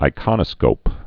(ī-kŏnə-skōp)